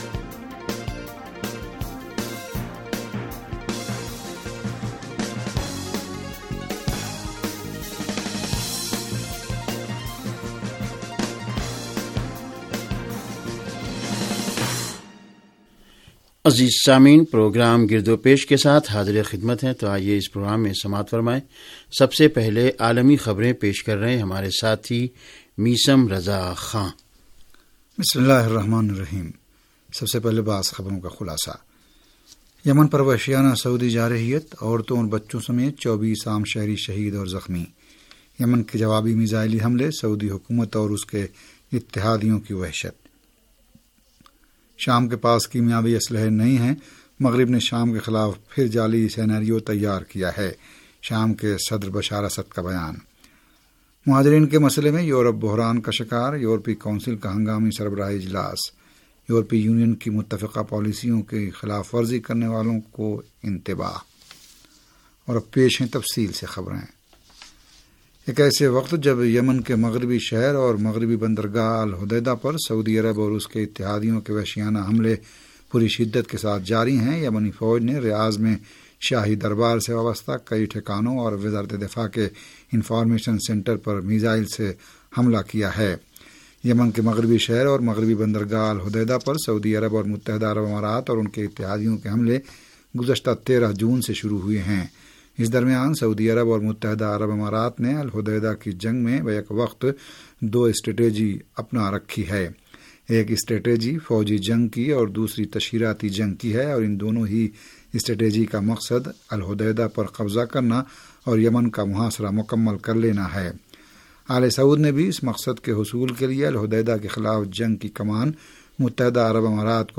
ریڈیو تہران کا سیاسی پروگرام گردوپیش و عالمی خبریں